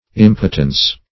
impotence - definition of impotence - synonyms, pronunciation, spelling from Free Dictionary
Impotence \Im"po*tence\, Impotency \Im"po*ten*cy\, n. [L.